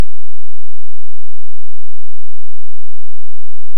Dauersignal im Eingang
Am Anfang hatte ich immer das Problem, dass ich ein Dauersignal auf dem Eingang hatte, das Mikrofon selber...